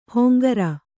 ♪ hongara